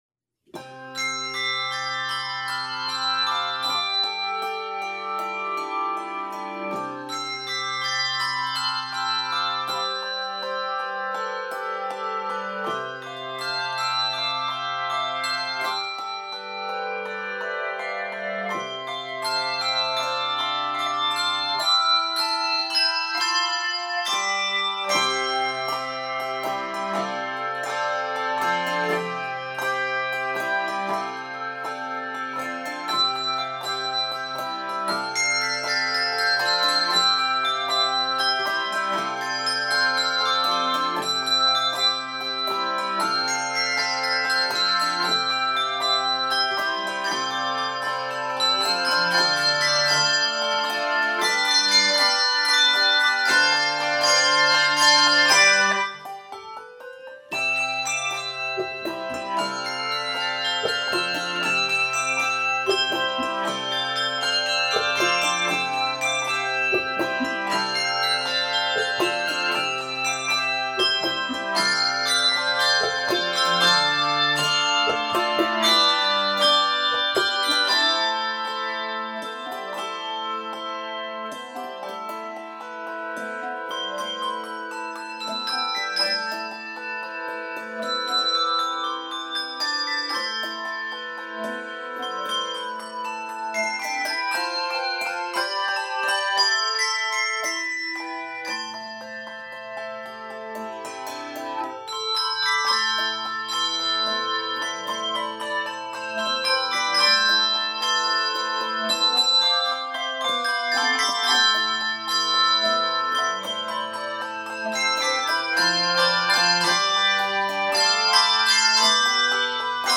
Voicing: Handbells 3-5 Octave